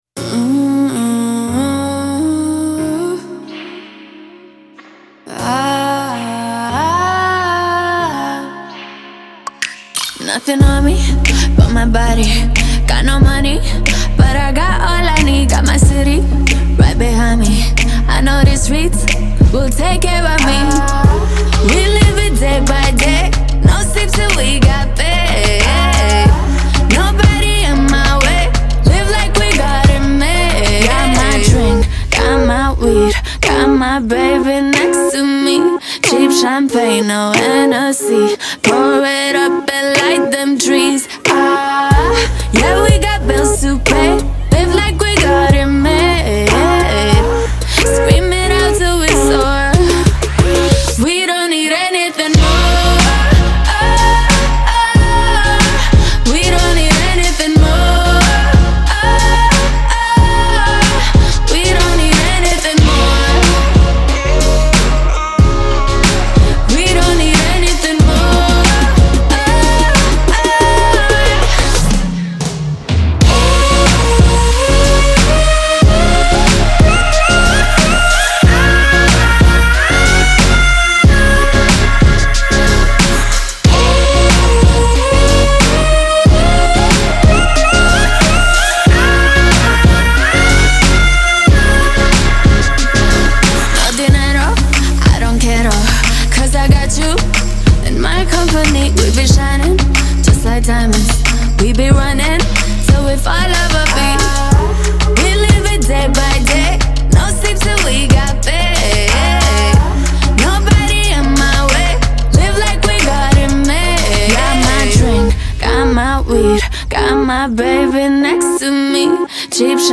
Genre: Electronic Dance